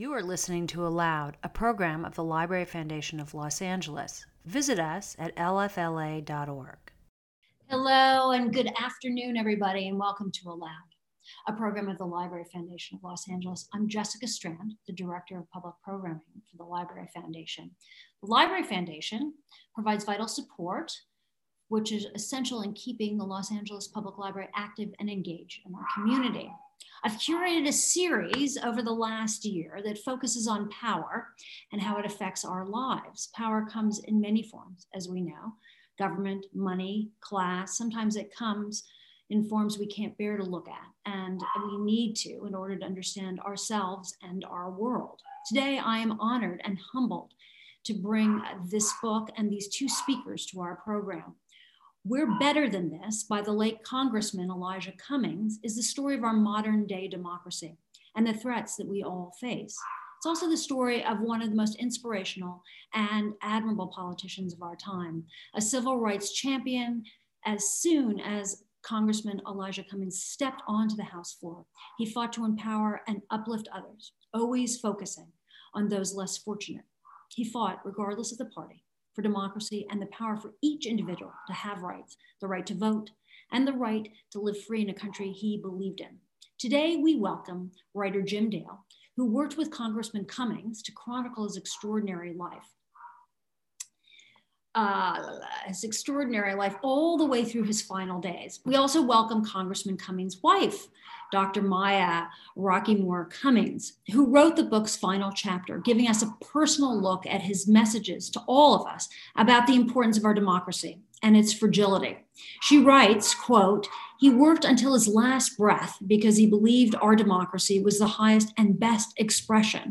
ALOUD is the Library Foundation of Los Angeles' award-winning literary series of live conversations, readings and performances at the historic Central Library and locations throughout Los Angeles.